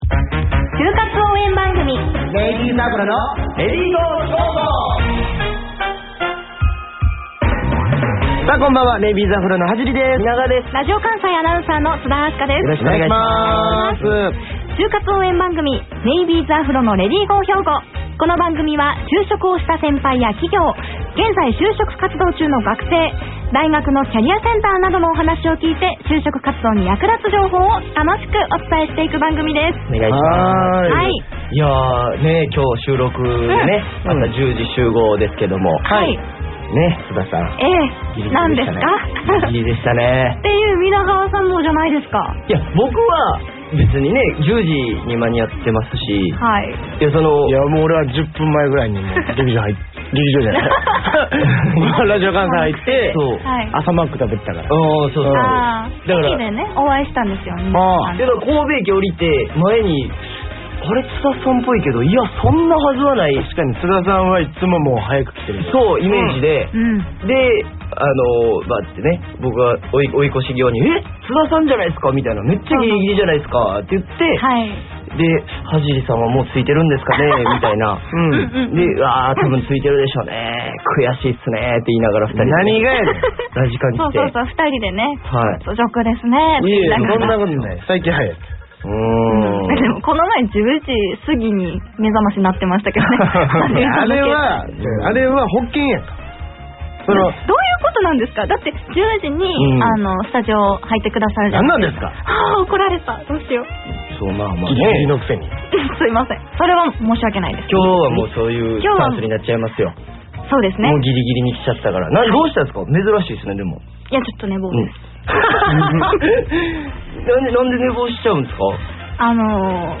『ネイビーズアフロのレディGO！HYOGO』2019年5月10日放送回（「就活レディGO！」音声）